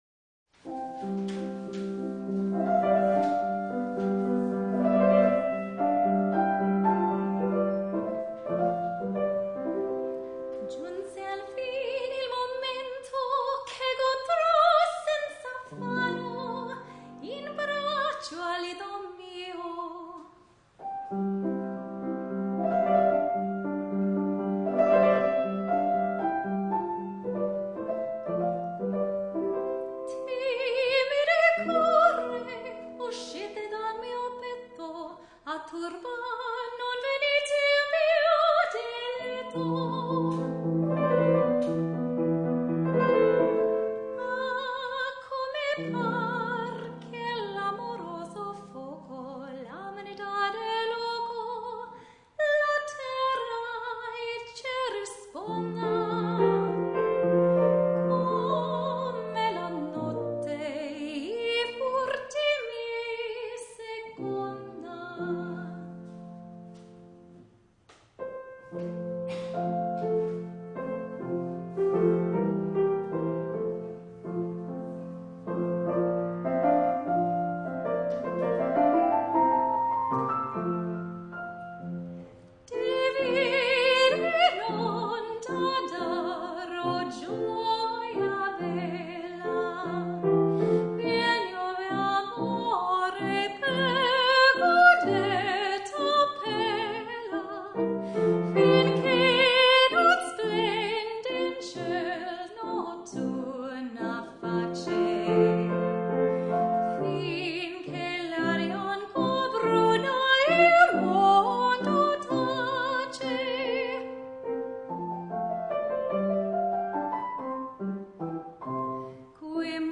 She is a performer, and has a magnificent opera voice.